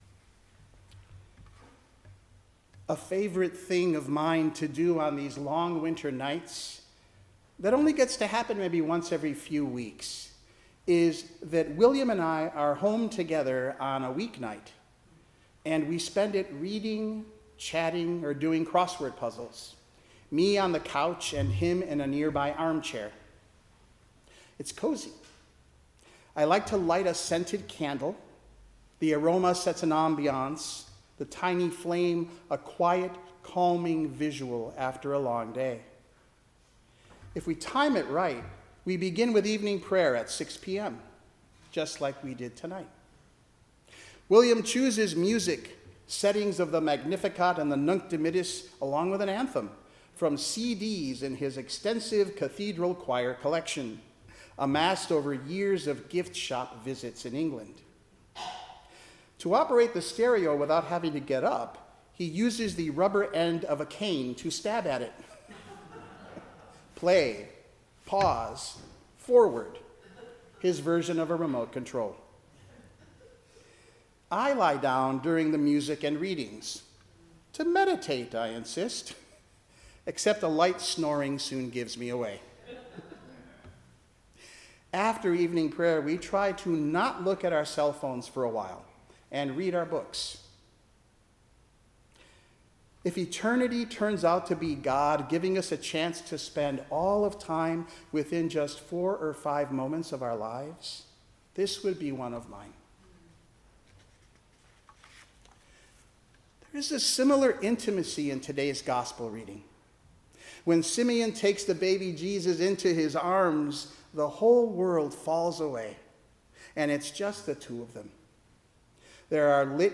Service Type: Special Day